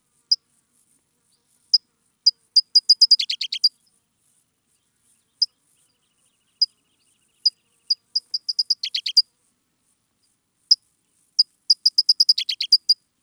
Sounds of the Escondido Creek Watershed
California Towee
ML - California Towee - edited.wav